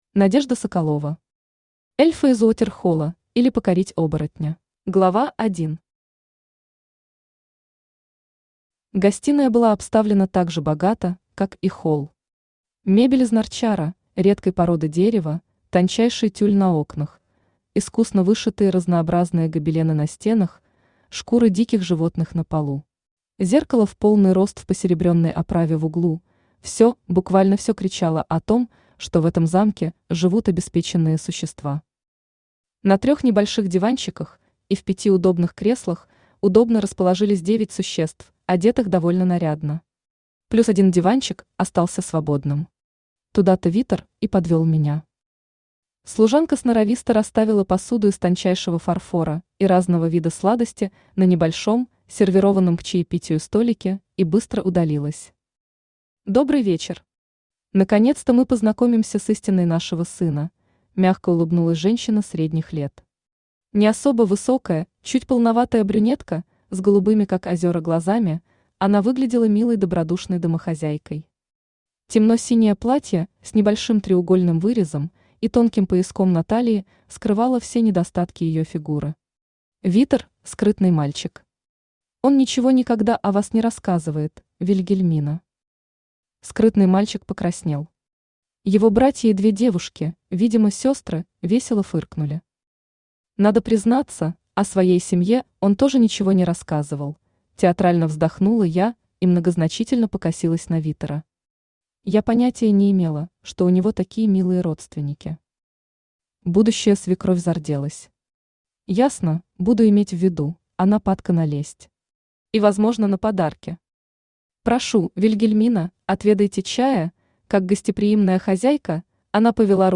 Аудиокнига Эльфы из Уотерхолла, или Покорить оборотня | Библиотека аудиокниг
Aудиокнига Эльфы из Уотерхолла, или Покорить оборотня Автор Надежда Игоревна Соколова Читает аудиокнигу Авточтец ЛитРес.